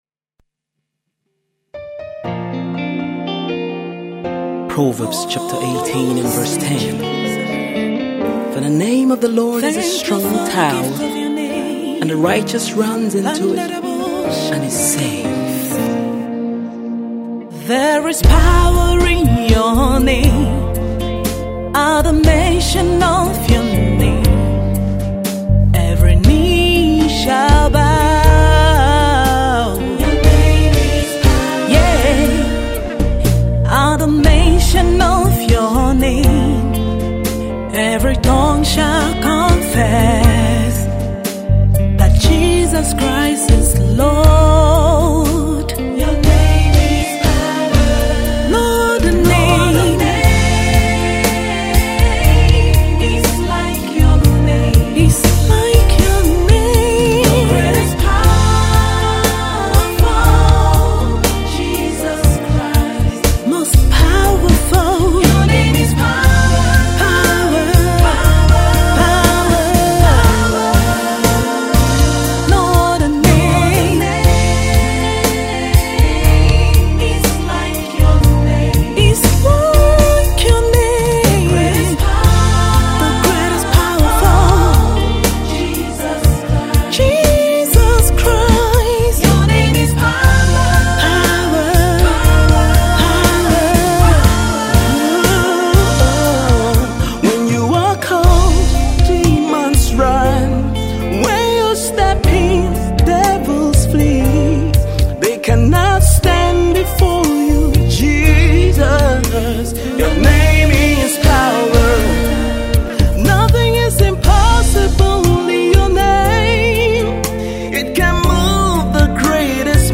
vocalist
slow rock worship song